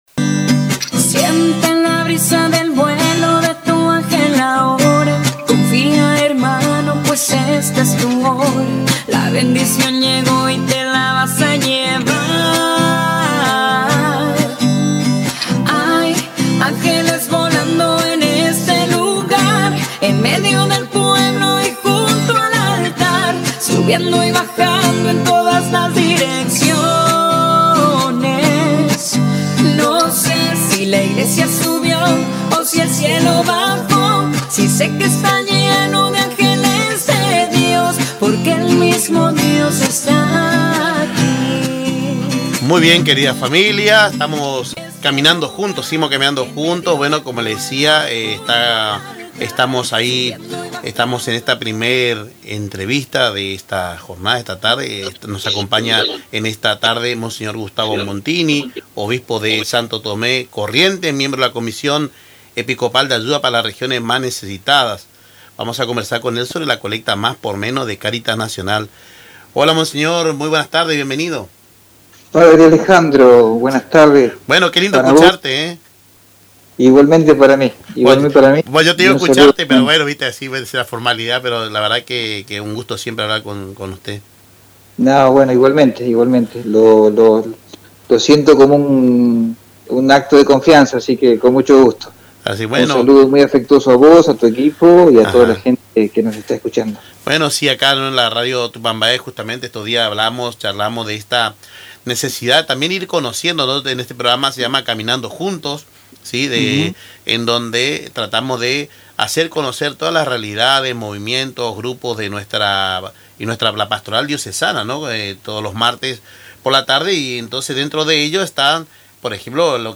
En una entrevista exclusiva con Radio Tupambaé, Mons. Gustavo Montini, obispo de Santo Tomé, Corrientes, y miembro de la Comisión Episcopal de Ayuda a las Regiones Más Necesitadas, destacó la importancia de la Colecta Más por Menos y explicó su funcionamiento.